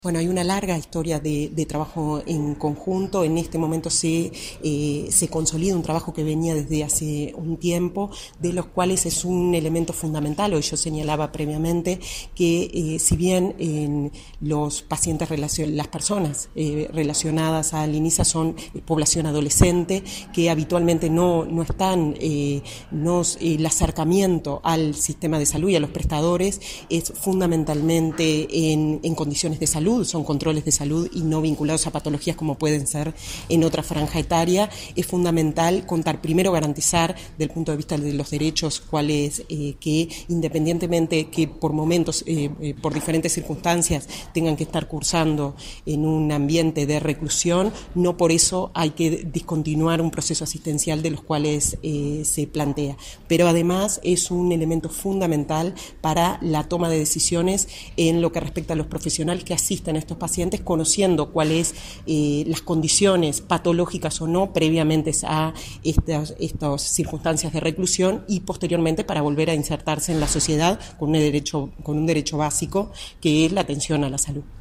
La vicepresidenta de ASSE, Marlene Sica, destacó que el convenio entre ese organismo e Inisa consolida una larga historia de trabajo conjunto. Dijo que es fundamental garantizar los derechos de los internos a la asistencia de salud y, para ello, la historia clínica electrónica es importante para la toma de decisiones de los profesionales que asisten a estos jóvenes en circunstancias de reclusión.